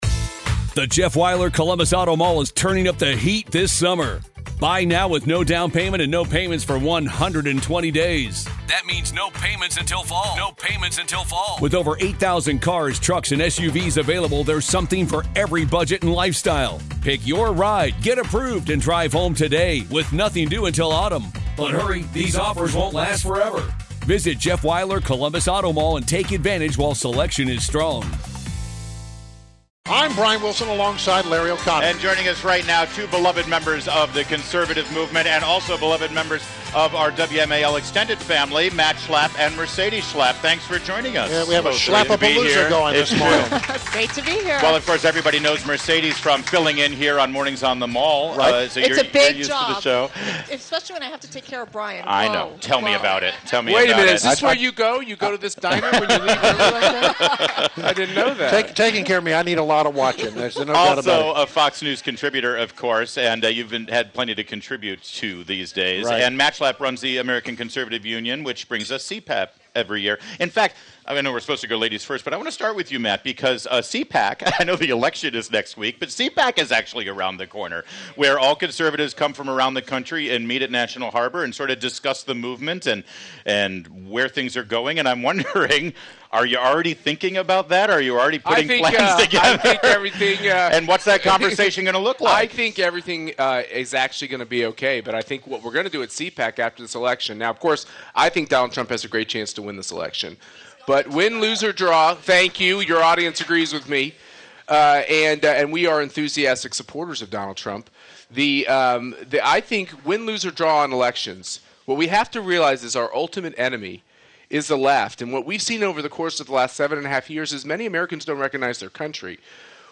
WMAL Interview - MERCEDES & MATT SCHLAPP - 11.04.16